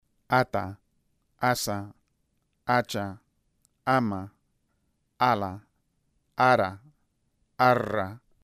Secuencias que demuestran los distintos modos de articulación de las consonantes.